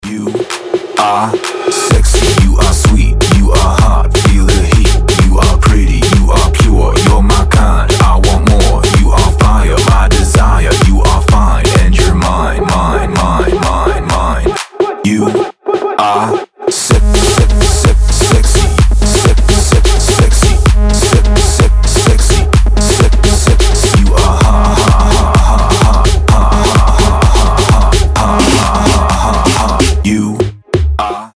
• Качество: 320, Stereo
мужской голос
ритмичные
мужской вокал
громкие
dance
EDM
club
Big Room